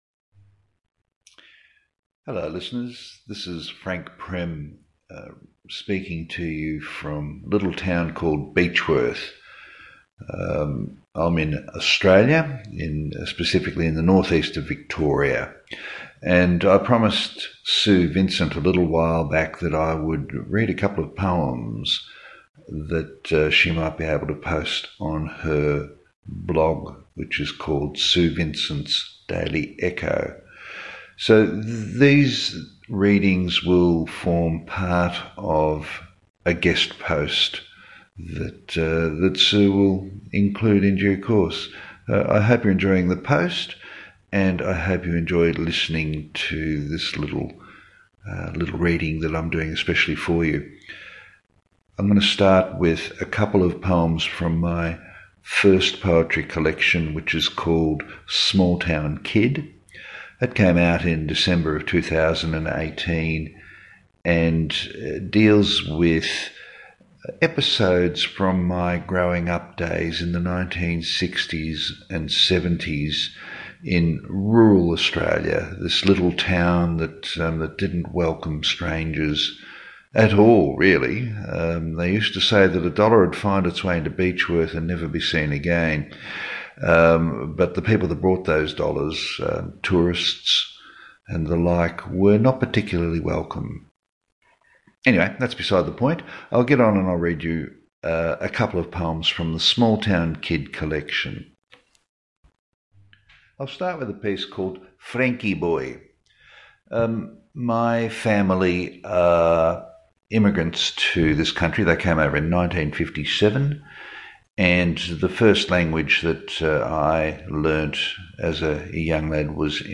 So much depth in voice.